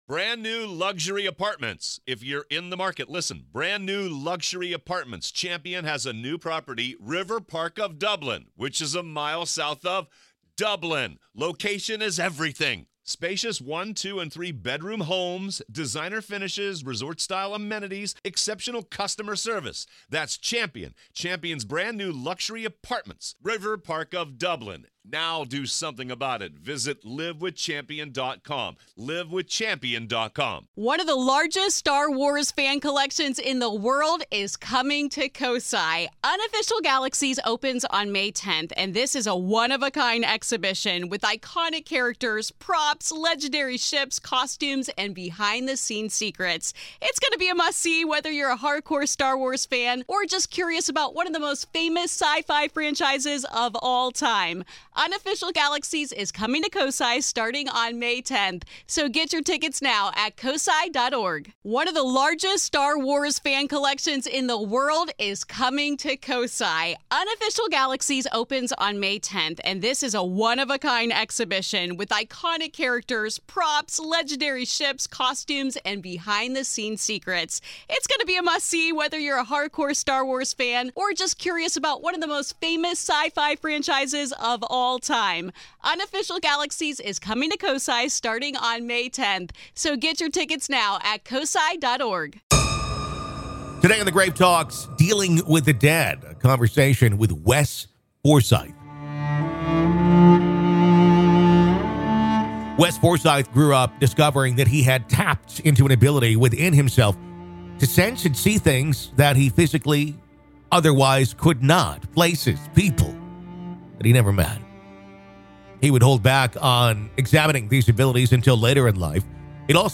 Dealing With The Dead | Conversation